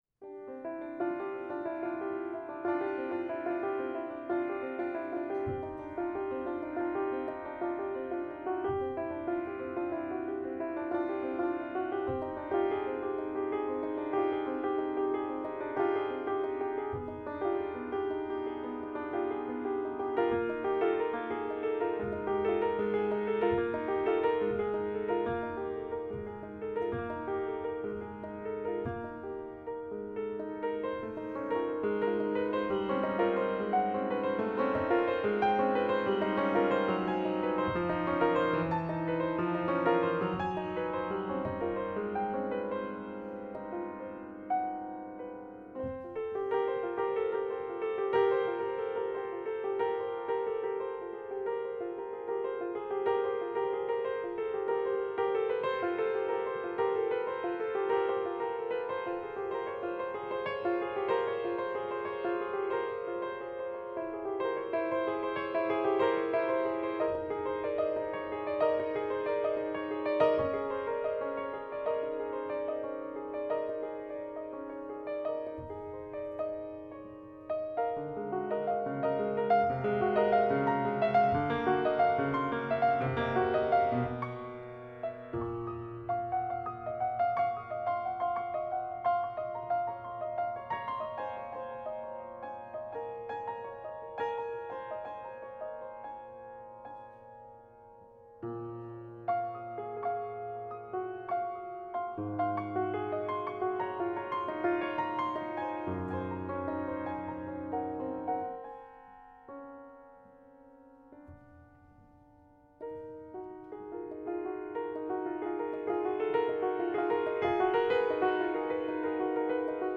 Benefit Concert
New School of Music, 25 Lowell St, Cambridge [directions]